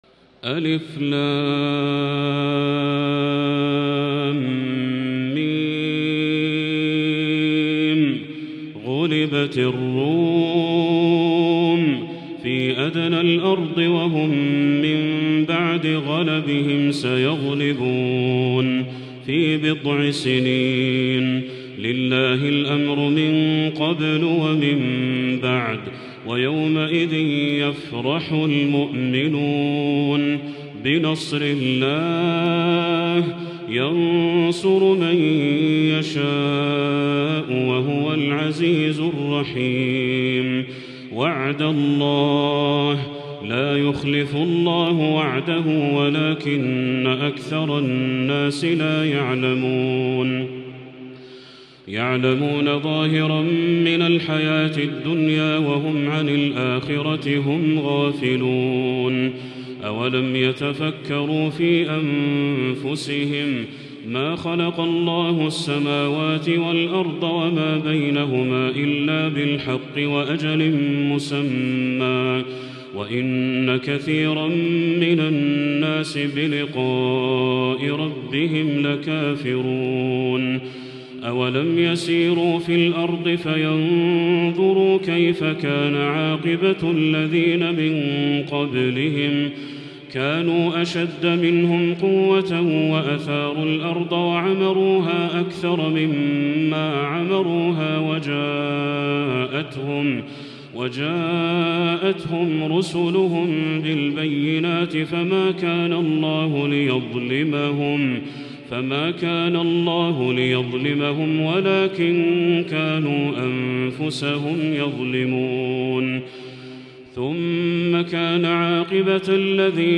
تراتيل إبداعية! | سورة الروم كاملة للشيخ بدر التركي من المسجد الحرام | Surat Ar-Rum Badr Al-Turki > السور المكتملة للشيخ بدر التركي من الحرم المكي 🕋 > السور المكتملة 🕋 > المزيد - تلاوات الحرمين